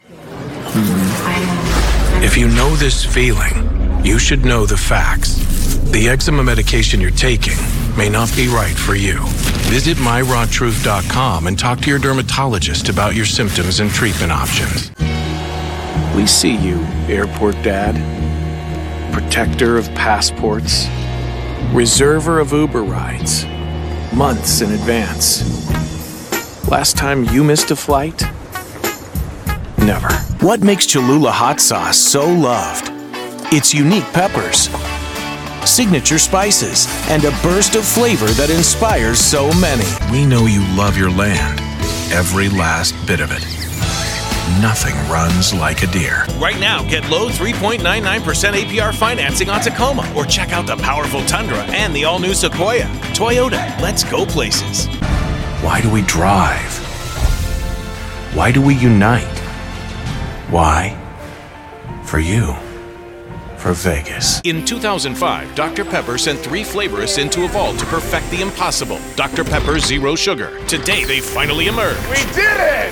Male Talent
COMMERCIAL DEMO